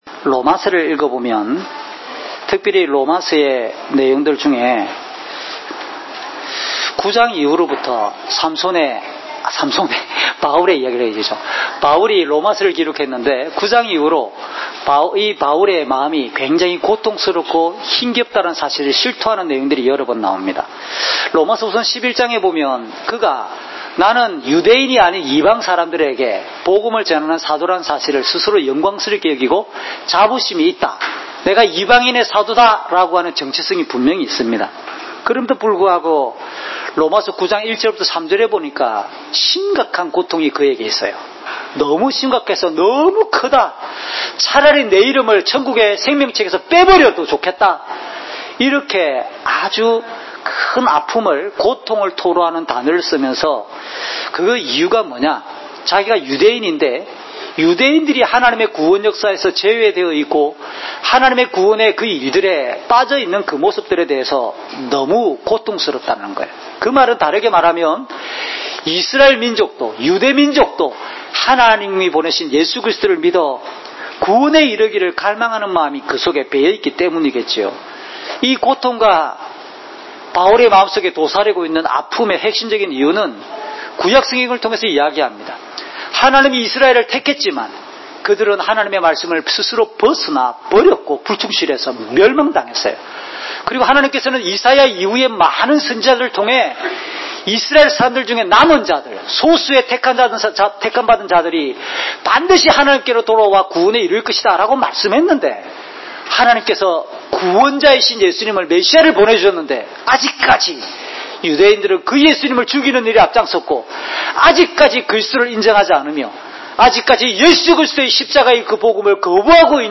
주일설교 - 2019년 7월 14일 “구원의 역사, 하나님의 완전한 지혜입니다!"(삿14:1~20)
주일 목사님 설교를 올립니다.